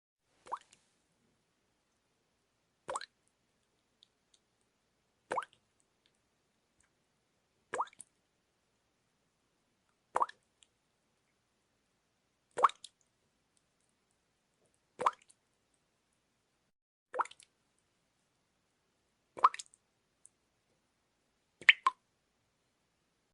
Watermill Wheel Dripping
Watermill Wheel Dripping is a free nature sound effect available for download in MP3 format.
Watermill Wheel Dripping.mp3